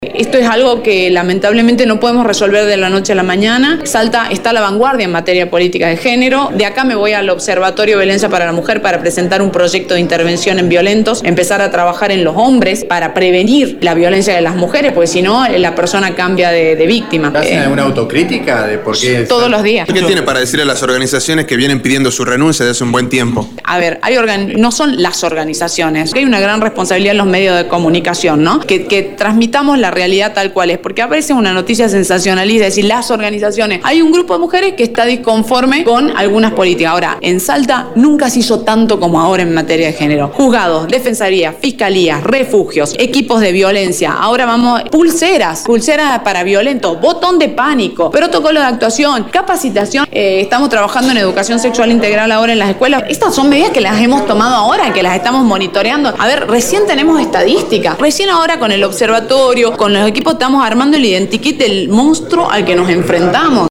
La ministra de derechos humanos y justicia, Pamela Calletti, se refirió a las políticas en violencia de género que se han desarrollado en la provincia.